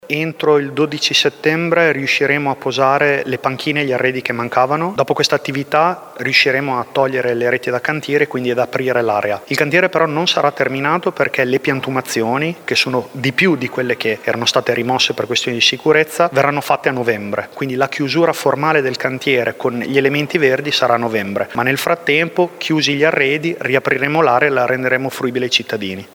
In tanti si chiedono quando il parchetto, molto frequentato da bambini e anziani, sarà restituito alla città. La risposta dell’assessore ai lavori pubblici Giulio Guerzoni: